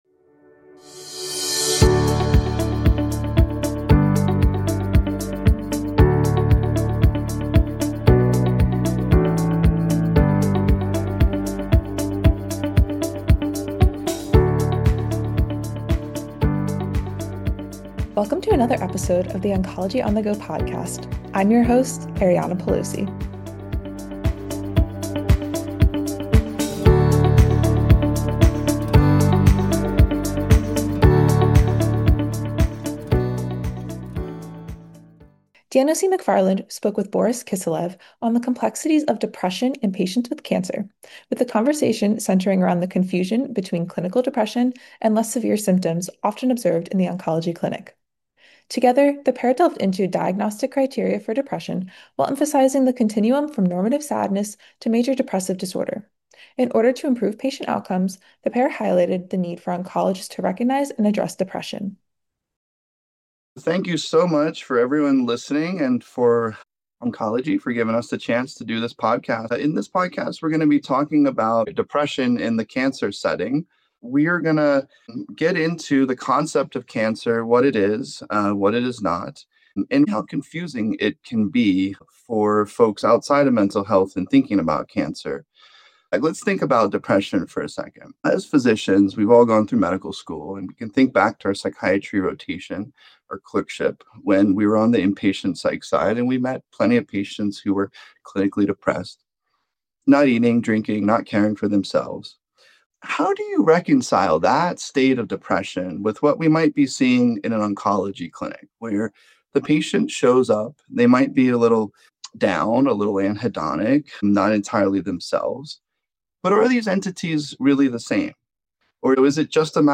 Together, they explored the complex intersection of oncology and psychiatry. The conversation challenged the oversimplification of “cancer-related sadness” to provide clinicians with a framework for distinguishing between normative grief and clinical Major Depressive Disorder (MDD).